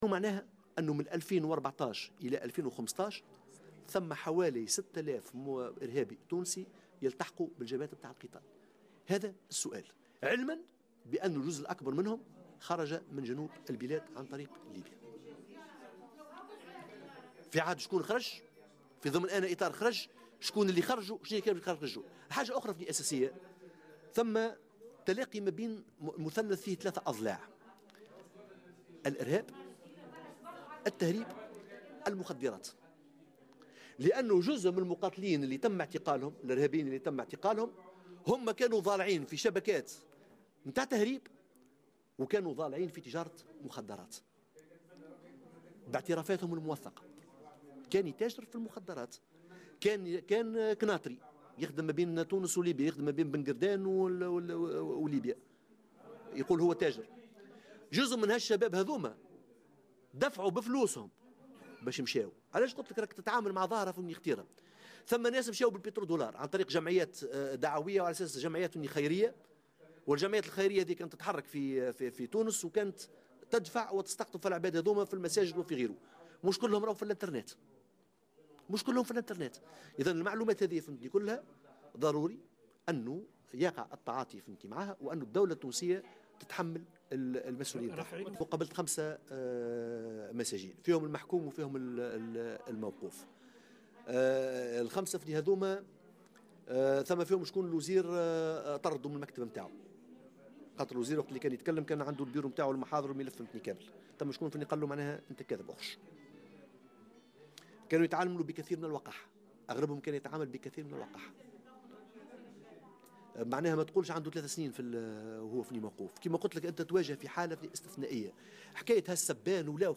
خلال الندوة الصحفية التي عقدها الوفد التونسي الذي زار سوريا مؤخرا إن 6 آلاف تونسي تمكنوا بين سنتي 2014 و2015 من الالتحاق بصفوف المقاتلين في سوريا.